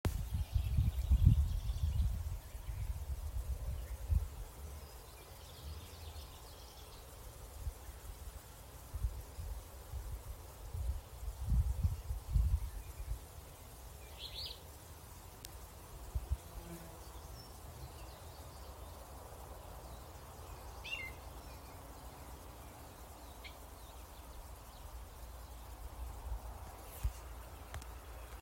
Thumbnails - Class: Aves - Alder Flycatcher ( Empidonax alnorum ) Quick Category filter...